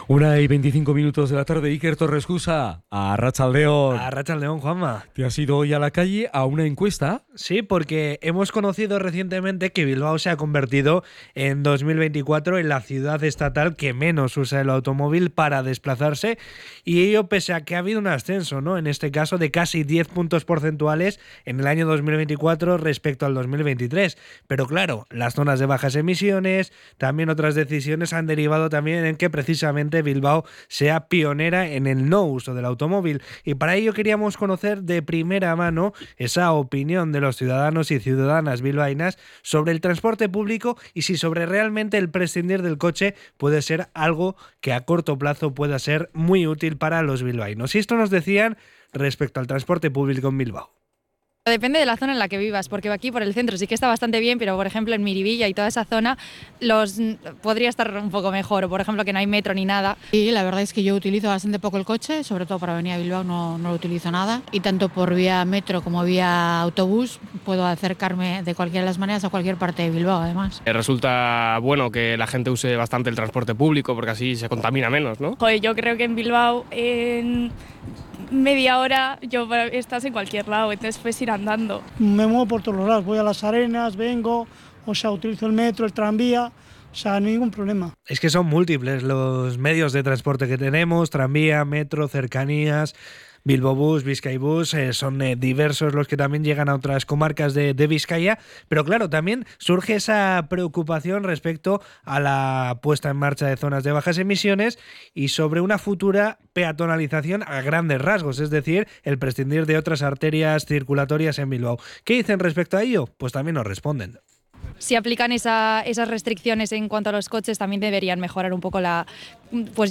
Salimos a la calle para conocer la opinión de los y las bilbaínas sobre el transporte en la ciudad
Radio Popular – Herri Irratia ha realizado una encuesta a pie de calle para conocer las opiniones de los ciudadanos sobre el estado del transporte público en Bilbao y su entorno.